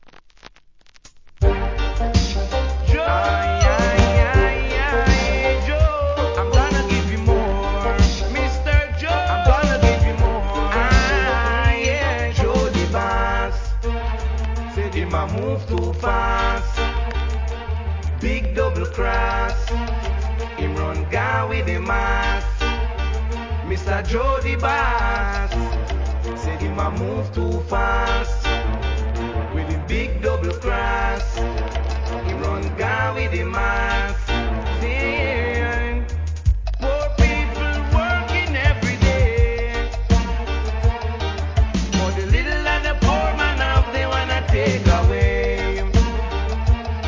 REGGAE
怒渋なDEEP ROOTS!!